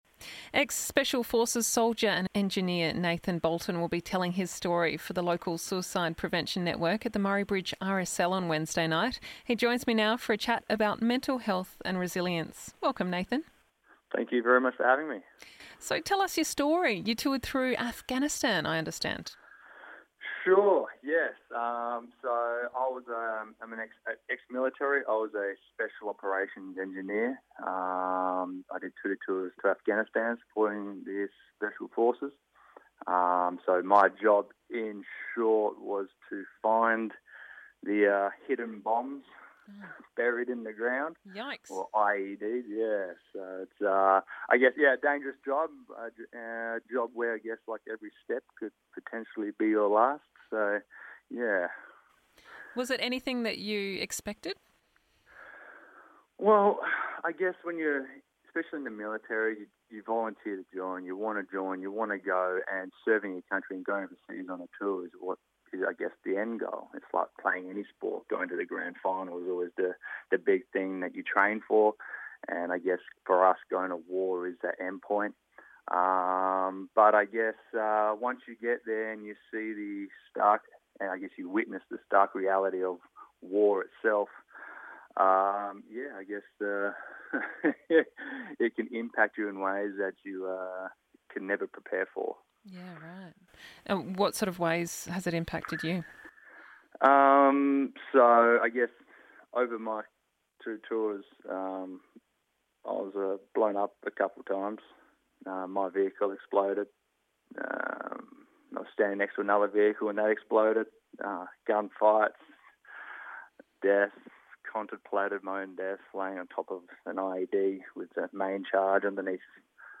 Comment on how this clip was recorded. on the line to tell his story